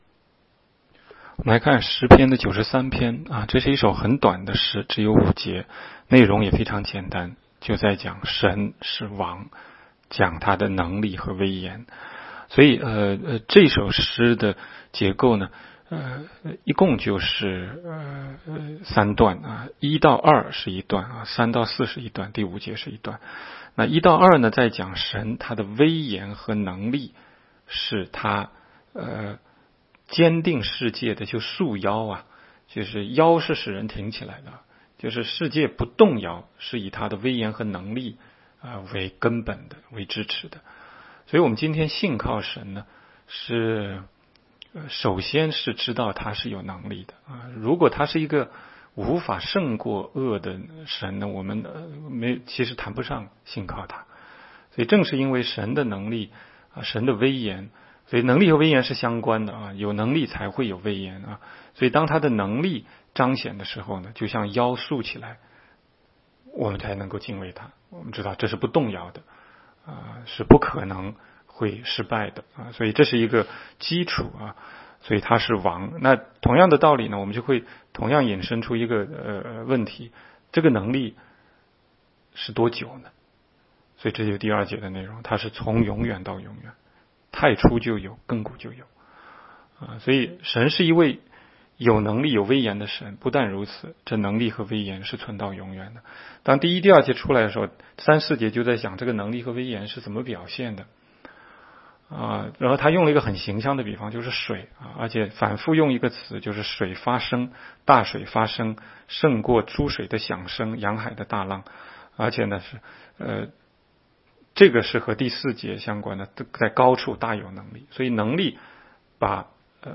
16街讲道录音 - 每日读经-《诗篇》93章